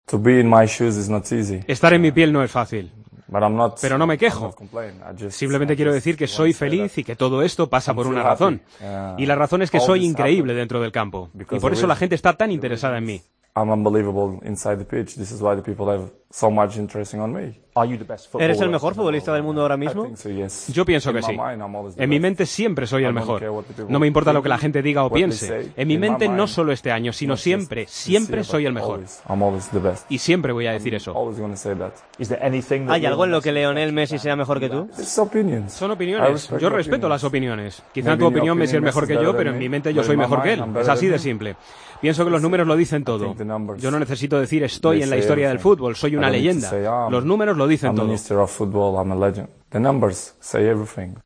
El atacante portugués del Real Madrid aseguró en una entrevista que publica este jueves la cadena británica BBC que es "el mejor jugador del mundo" y se mostró convencido de que está al nivel de los más grandes de la historia.